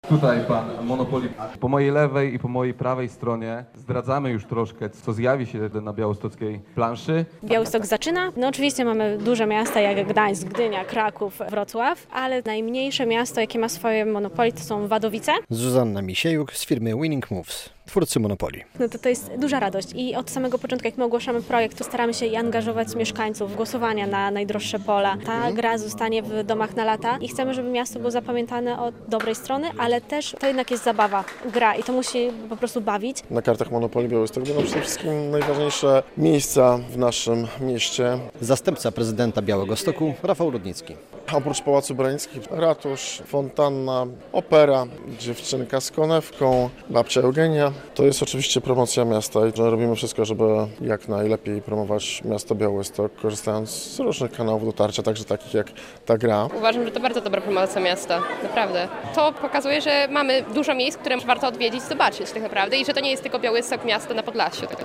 Promocja miasta poprzez Monopoly - relacja